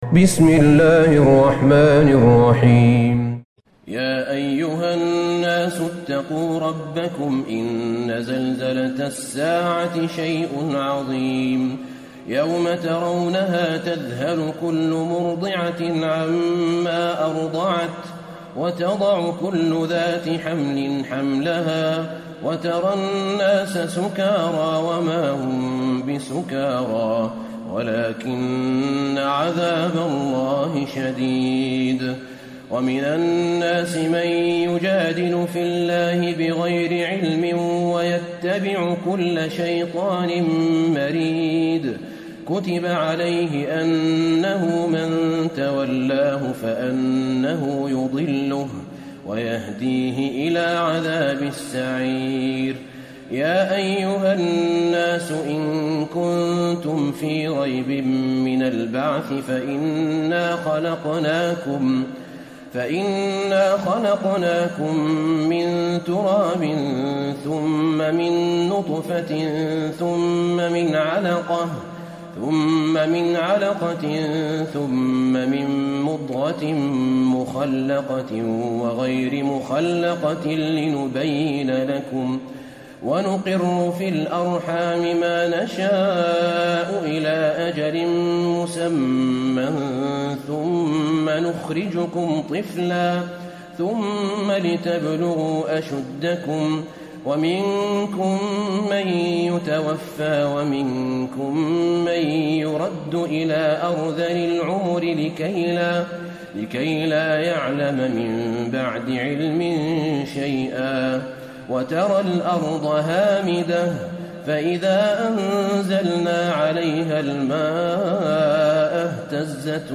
سورة الحج Surat Al-Hajj > مصحف الشيخ أحمد بن طالب بن حميد من الحرم النبوي > المصحف - تلاوات الحرمين